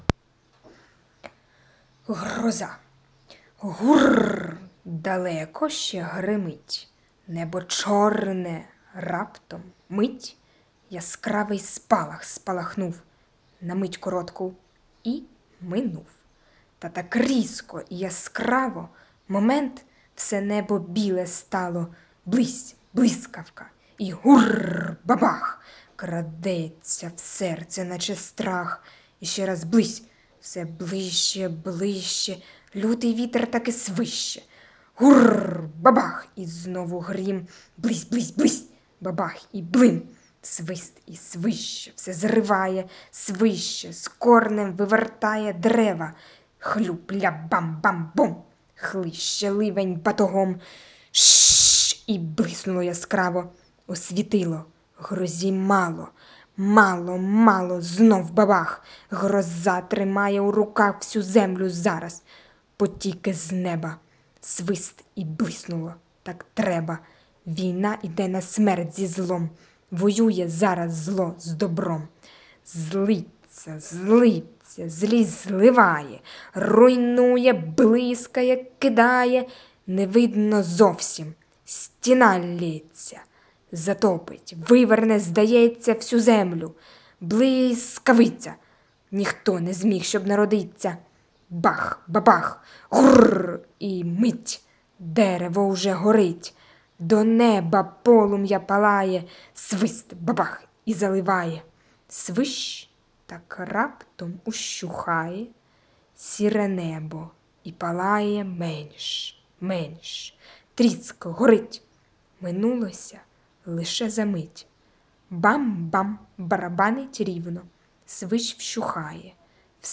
Гроза Мій вірш у моєму виконанні.mp3